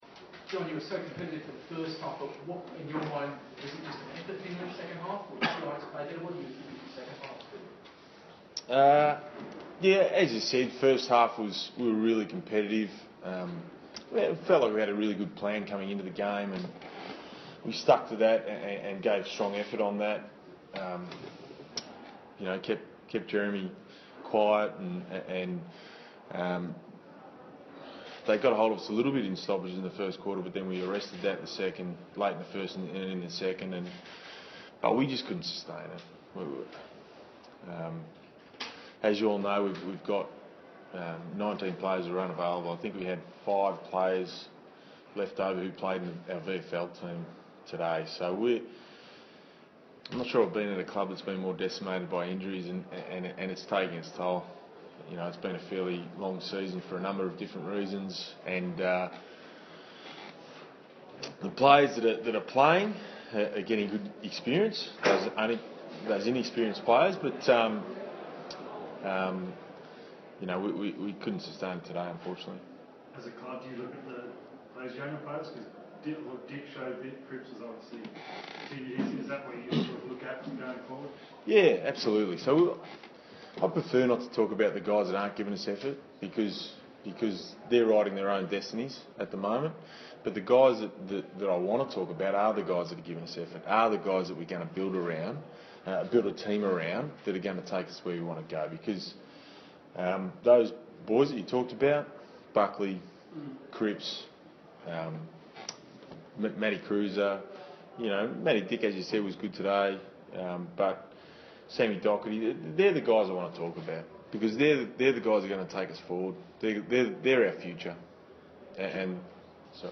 Round 22 post-match press conference